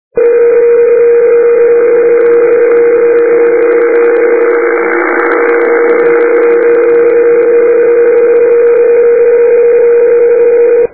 Les fichiers à télécharger sont compressés au format MP3 à 1ko/sec, ce qui explique la très médiocre qualité du son.
Il utilise la modulation de phase par tout ou rien, ce qui explique le son monotone légèrement chevrotant.
bande 15 m, émission PSK31